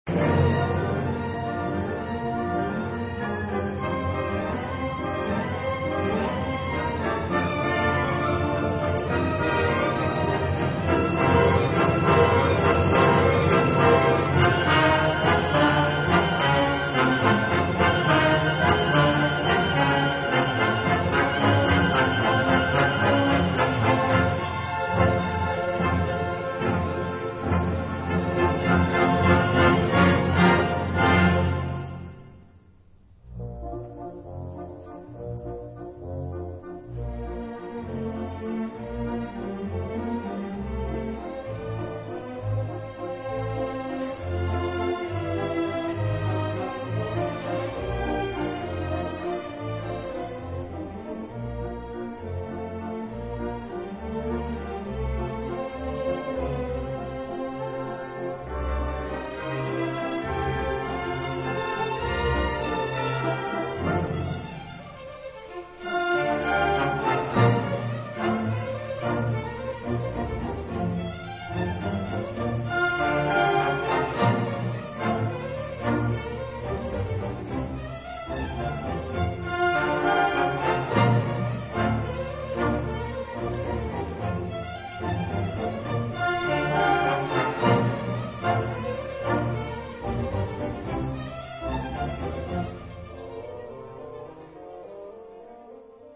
下記のMP3ファイルはナローバンド用モノラル音声です。
(24〜32Kbps, Monaural Sound MP3 files)